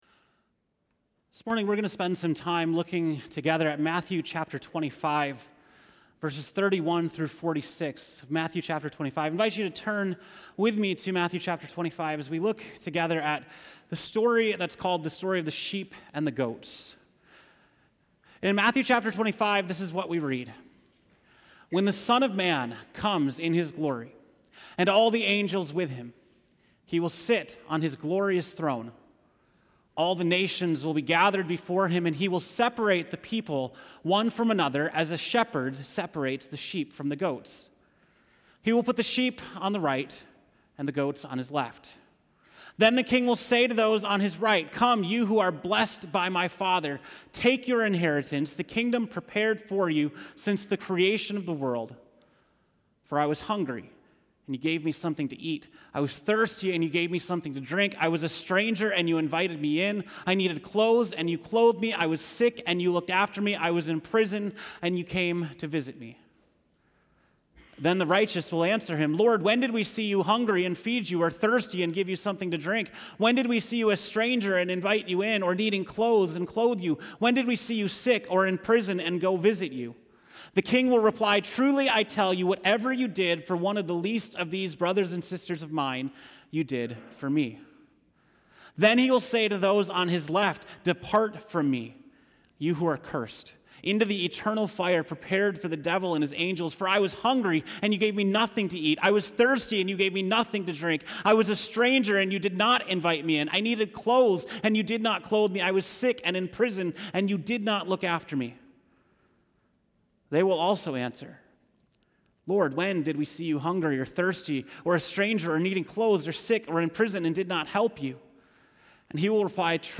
September 15, 2019 (Morning Worship)